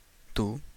Ääntäminen
Synonyymit huevón vos Ääntäminen Tuntematon aksentti: IPA: [tu] Haettu sana löytyi näillä lähdekielillä: espanja Käännös Konteksti Ääninäyte Pronominit 1.